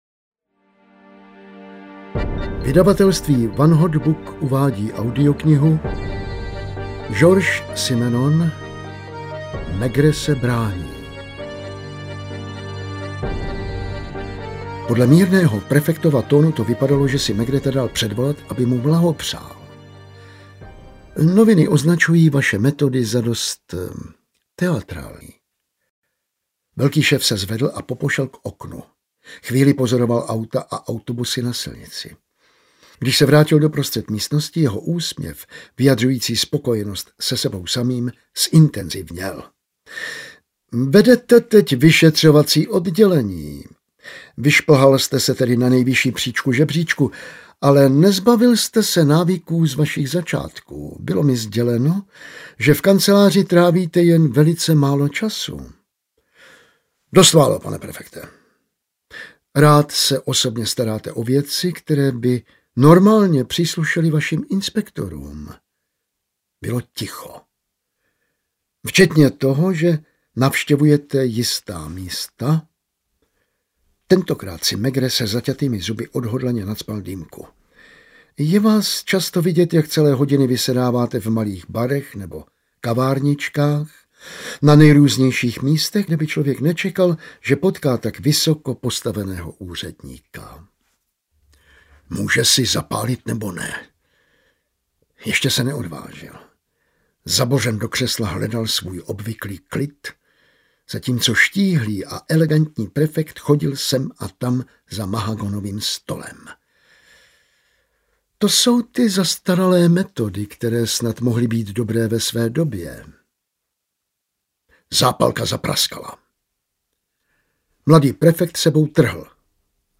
Maigret se brání audiokniha
Ukázka z knihy